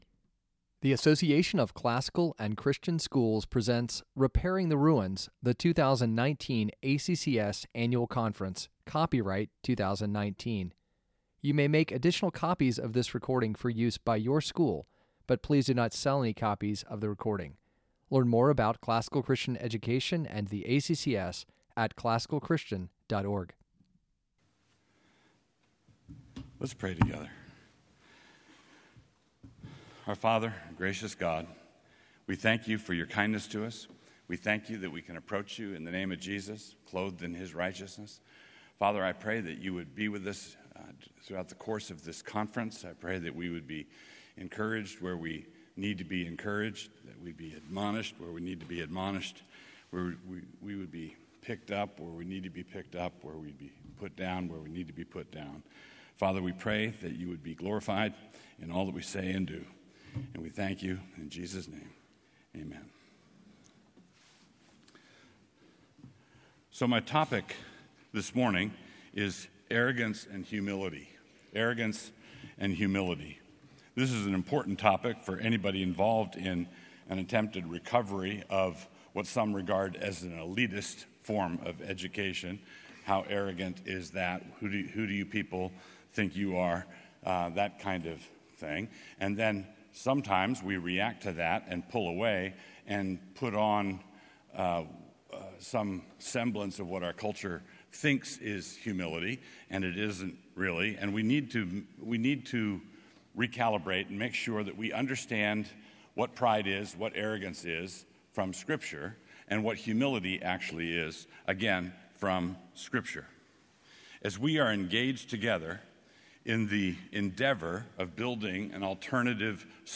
2019 Plenary Talk | 54:27 | All Grade Levels, Culture & Faith